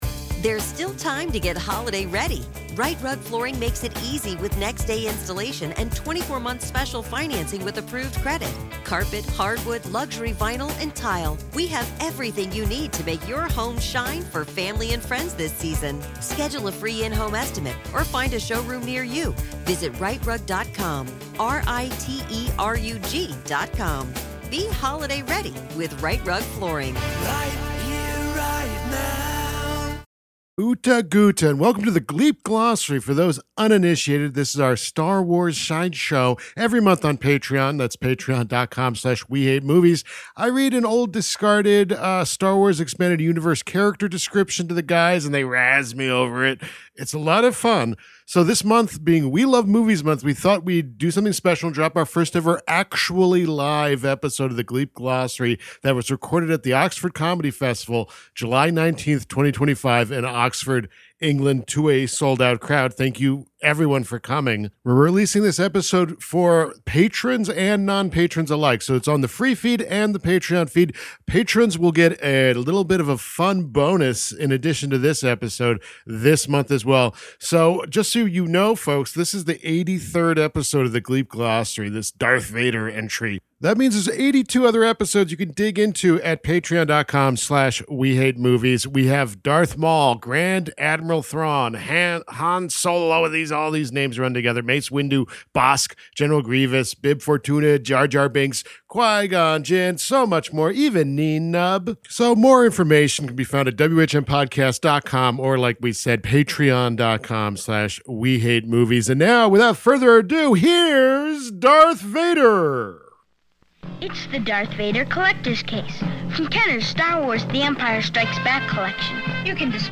The gang did their best, playing to a sold-out crowd in England, to try and find out.